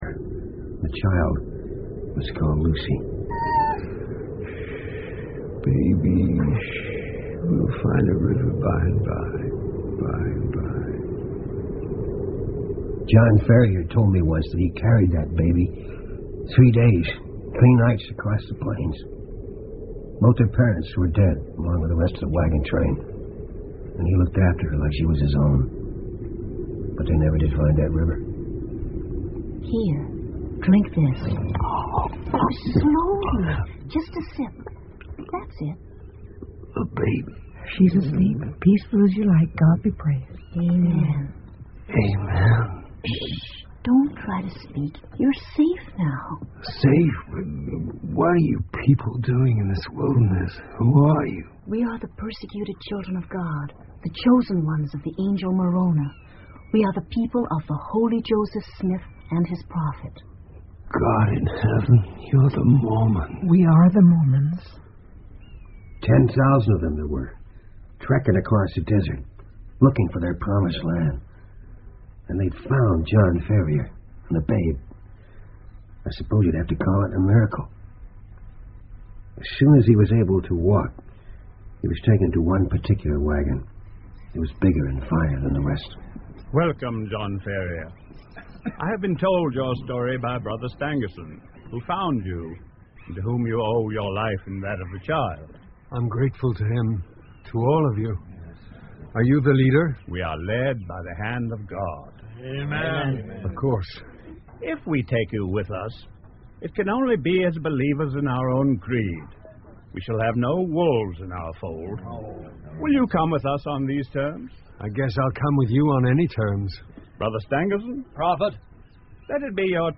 福尔摩斯广播剧 A Study In Scarlet 血字的研究 17 听力文件下载—在线英语听力室